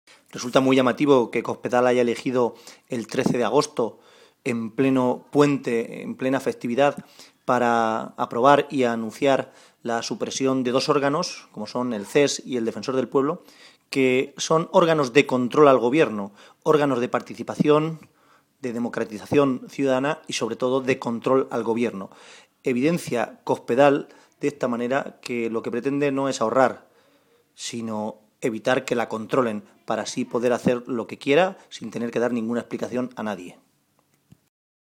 José Manuel Caballero, secretario de Organización del PSOE de C-LM
Cortes de audio de la rueda de prensa